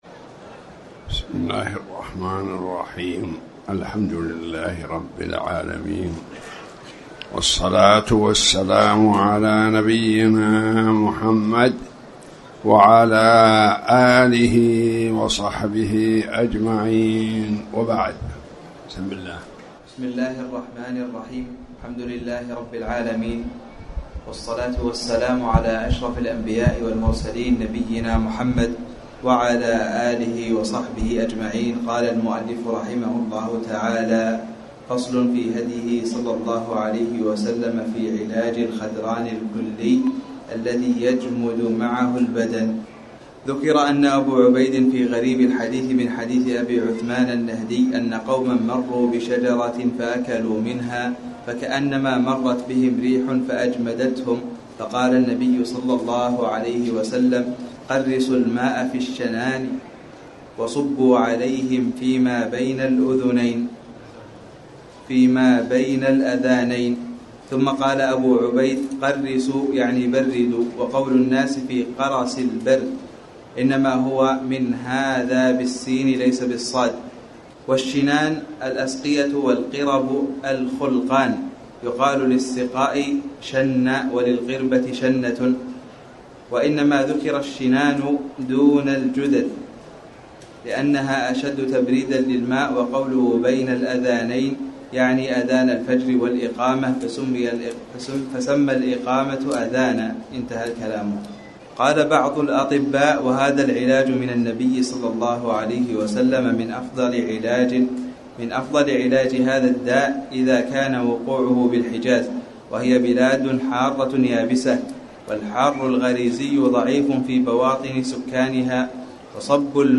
تاريخ النشر ٦ رجب ١٤٣٩ هـ المكان: المسجد الحرام الشيخ